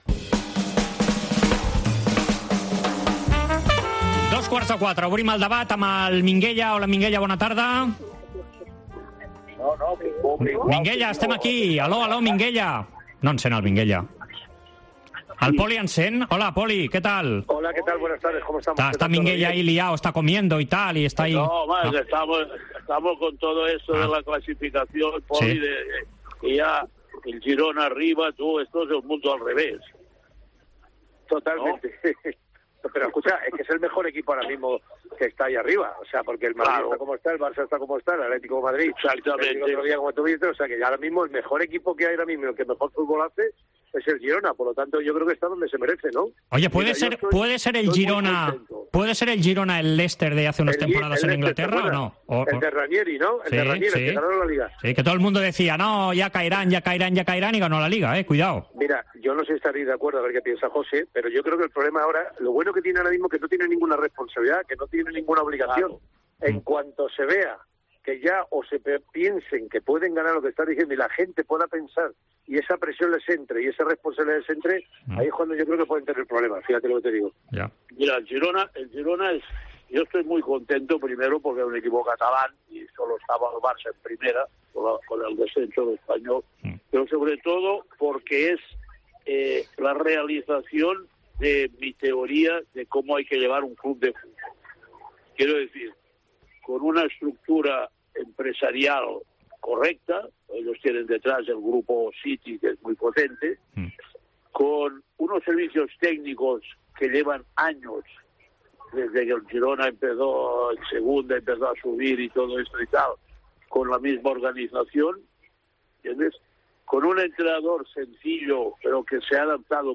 El debat Esports COPE, amb Poli Rincón i Minguella
AUDIO: Els dos col·laboradors de la Cadena COPE repassen l'actualitat esportiva de la setmana.